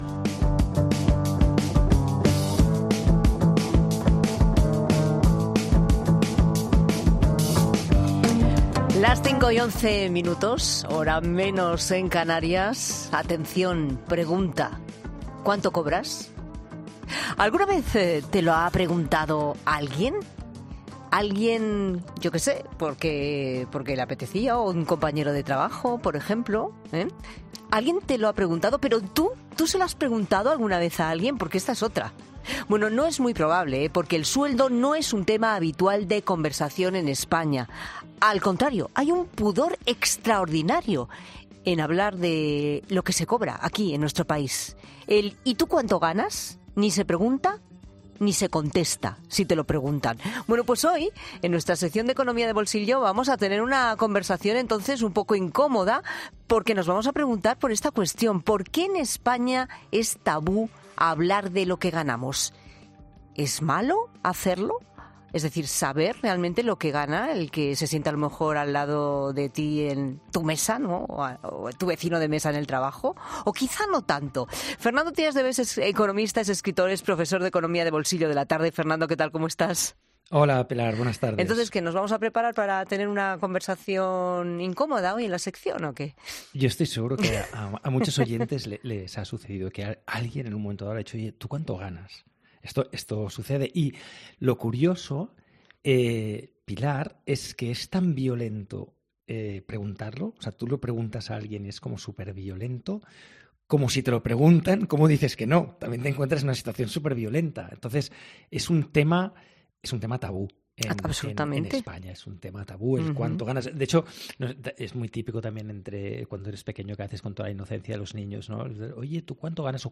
Fernando Trías de Bes, el economista de bolsillo de La Tarde Fernando Trías de Bes, el economista 'de bolsillo' de La Tarde ¿Cuándo sería bueno saber lo que cobran los demás?